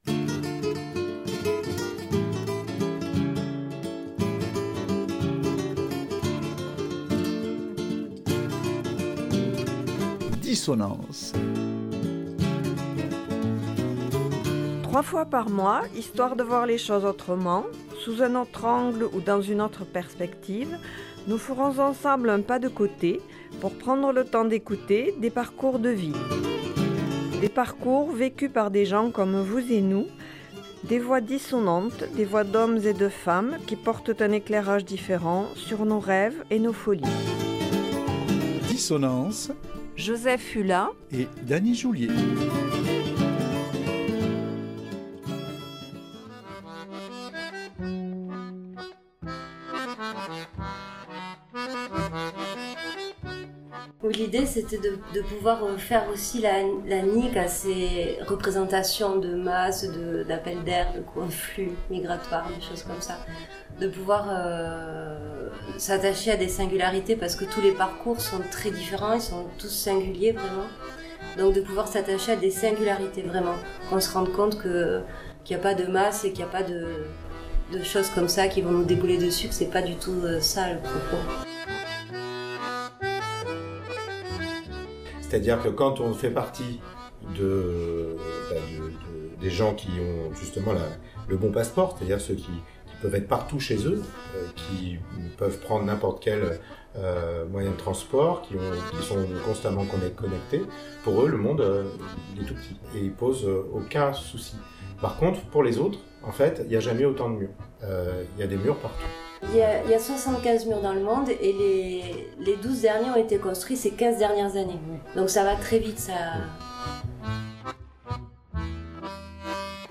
Cette émission est produite et réalisée par Radio Saint Affrique; diffusion mardi 25 mars 2025, mercredi 26 mars 2025, samedi 29 mars 2025.